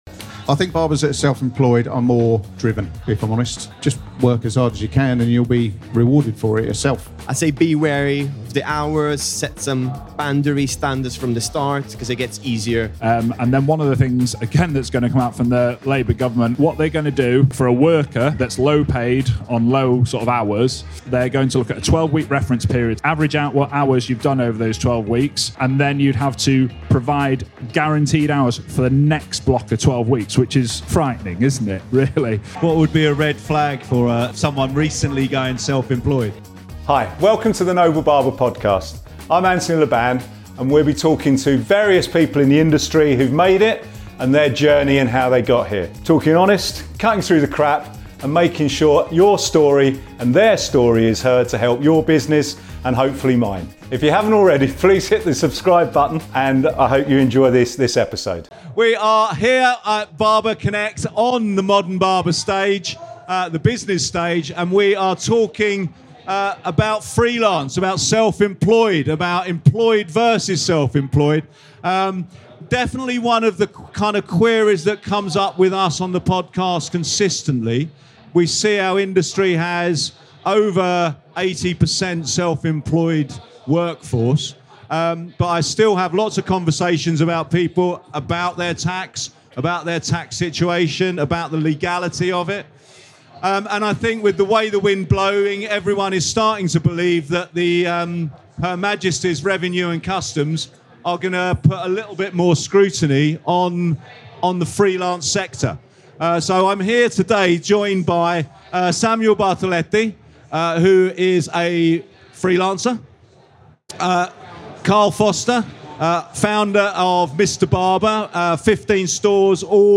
Self Employed vs Employed Barbers, Which Is Best? The UK Laws | Barber Connect 2025 (LIVE)